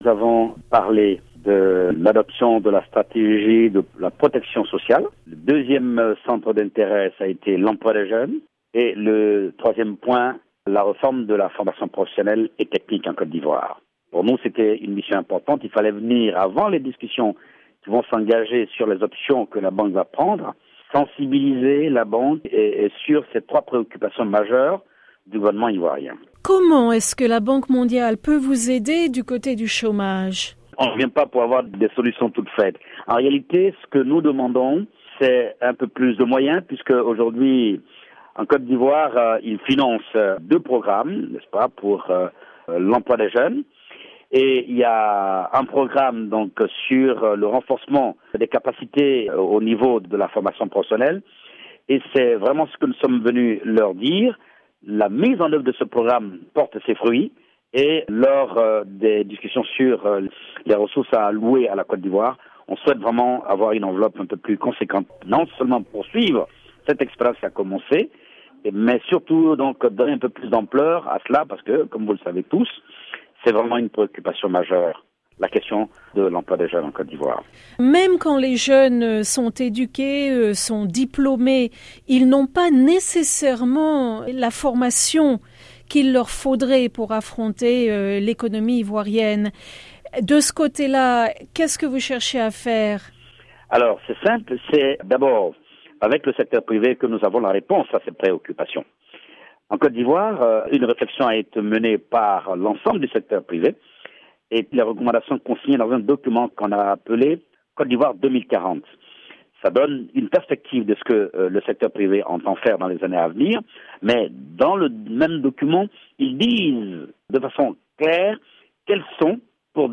Ecoutez M Moussa Dosso
« Pour nous, c’est une mission importante », a souligné M. Moussa Dosso dans une interview avec la VOA.